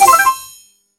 Win SFX Light.mp3